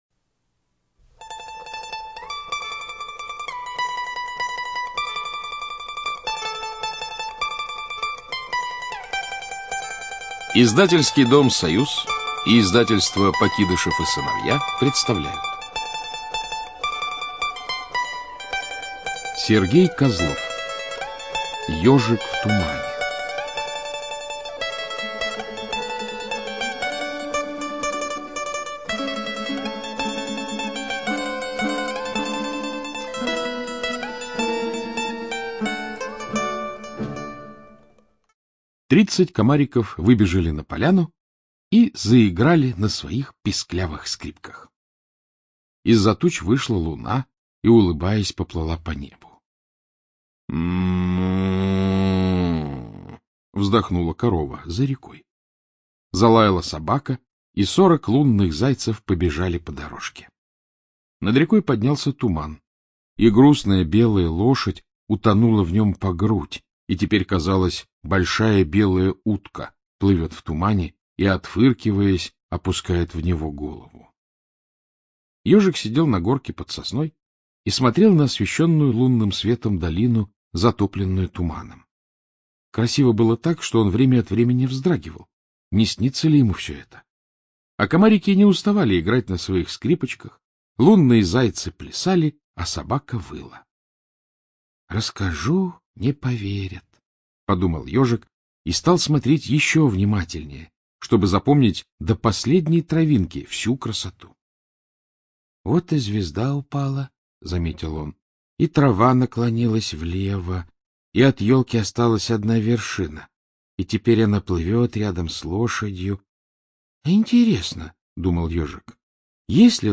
ЧитаетКлюквин А.
Студия звукозаписиСоюз